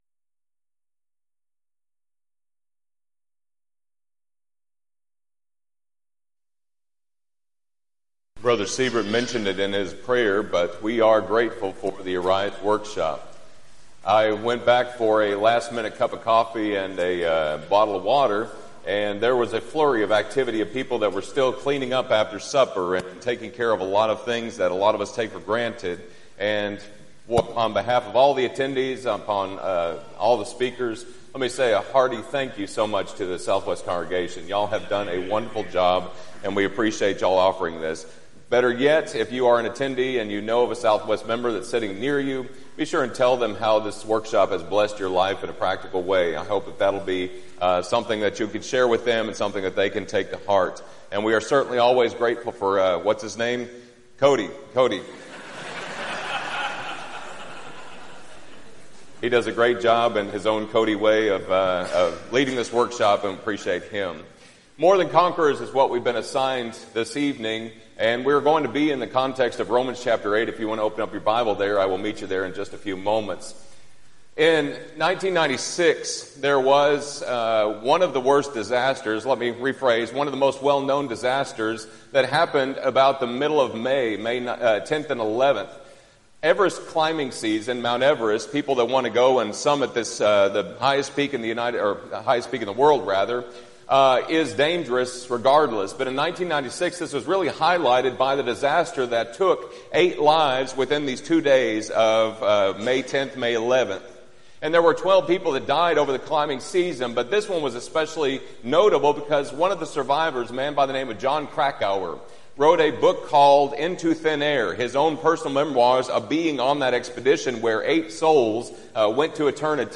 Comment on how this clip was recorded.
Event: 6th Annual Southwest Spiritual Growth Workshop